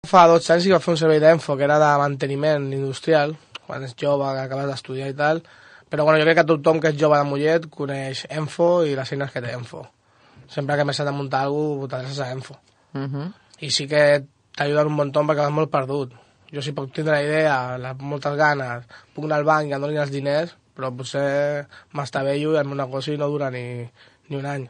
Avui volem compartir amb vosaltres un tall de veu molt breu del programa “Posa’t les piles” que es va emetre aquest dilluns. En aquest vam parlar dels serveis d’EMFO adreçats a emprenedors i a empreses, i més en concret, en aquest tall se cita a EMFO com a referent  pels joves de Mollet a l’hora de muntar un projecte: